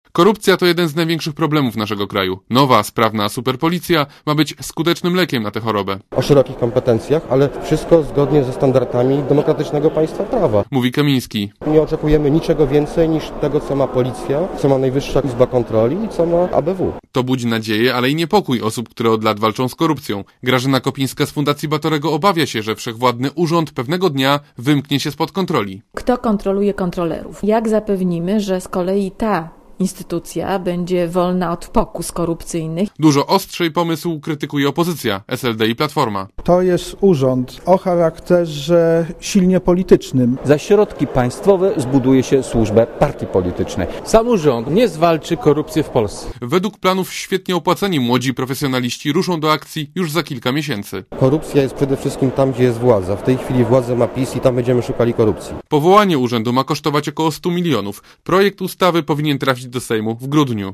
Jego przyszły szef Mariusz Kamiński zapowiada, że CUA może zacząć działać już w lutym. 09.11.2005 | aktual.: 10.11.2005 08:36 ZAPISZ UDOSTĘPNIJ SKOMENTUJ Relacja reportera Radia ZET